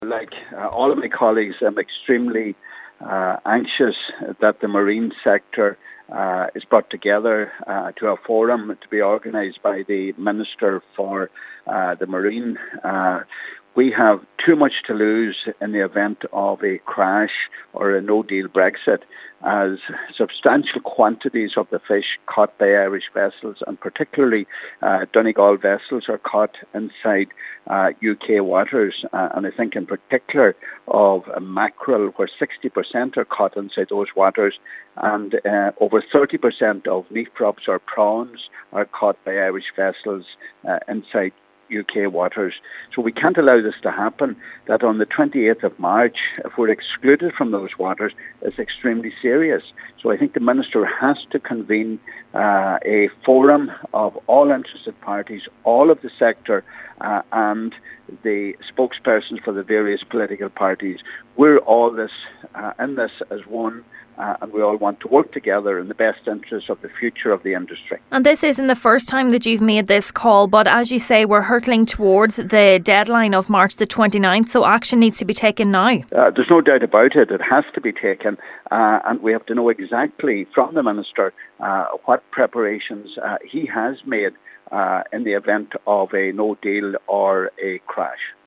He says those sectors will be hit hardest in the event of a no deal Brexit: